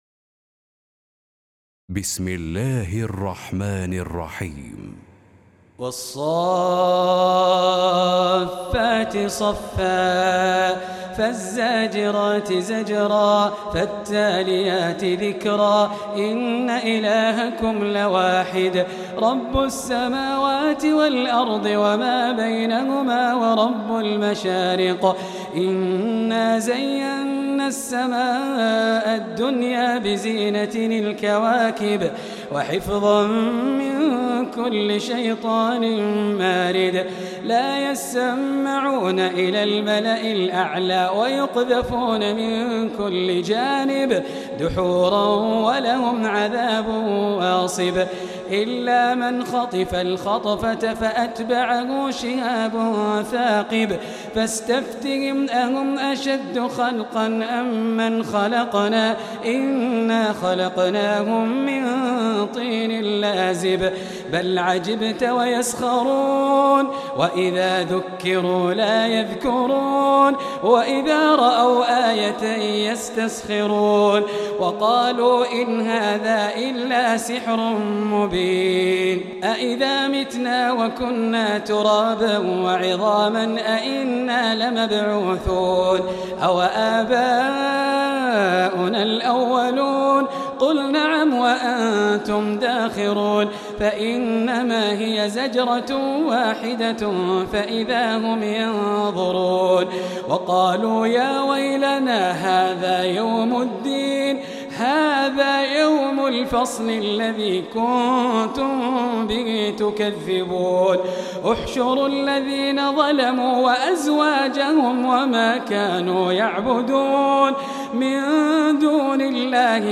تلاوة وقراءة بصوت أفضل القراء